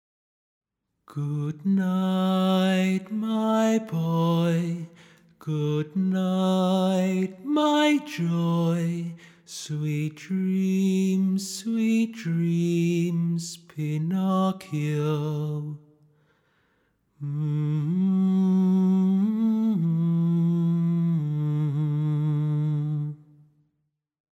Pinocchio-Lullaby-Song.mp3